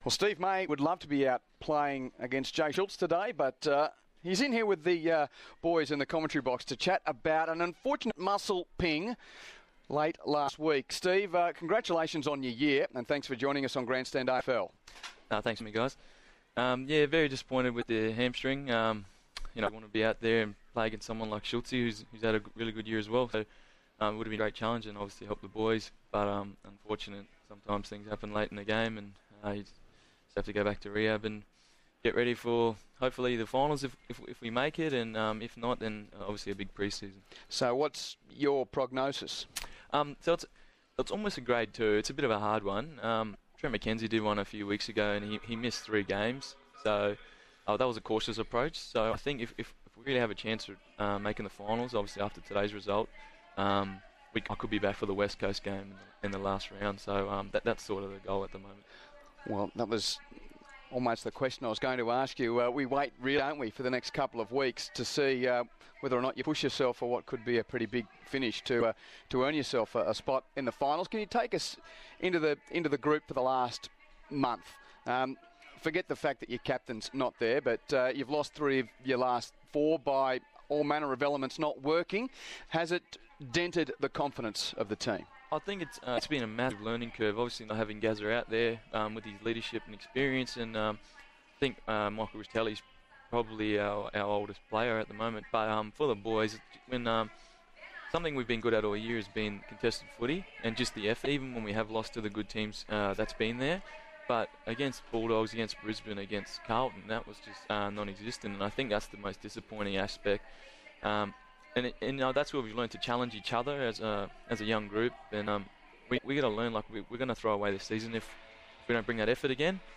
Defender Steven May chats with ABC Radio's Saturday Agenda ahead of the game against Port Adelaide.